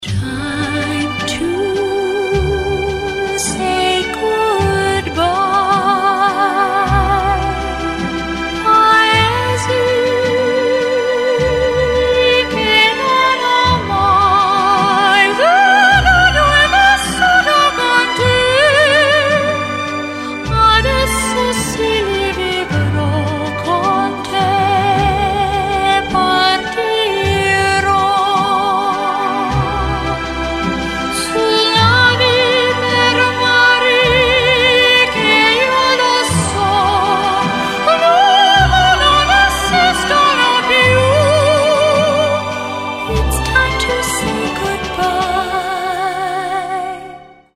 классические